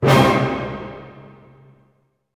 Index of /90_sSampleCDs/Roland LCDP08 Symphony Orchestra/HIT_Dynamic Orch/HIT_Orch Hit Min
HIT ORCHM00L.wav